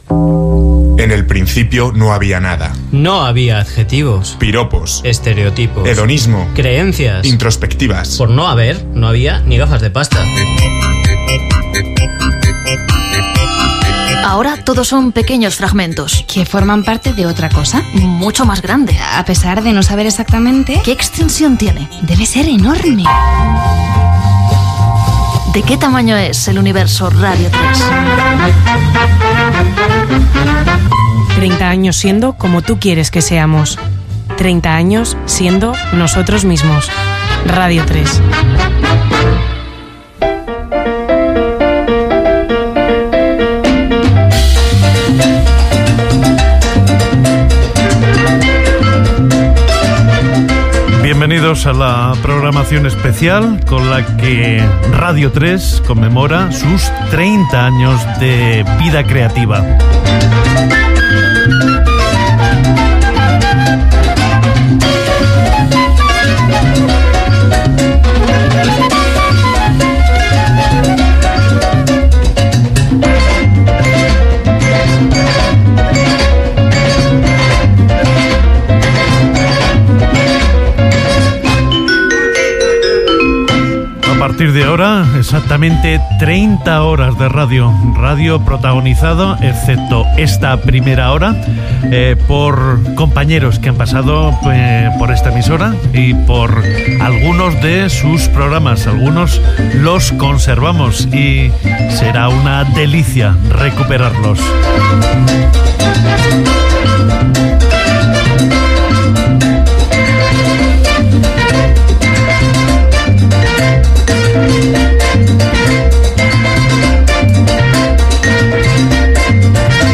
Indicatiu dels 30 anys de la ràdio, inici de la programació especial de 30 hores per celebrar els 30 anys de la ràdio.
Entreteniment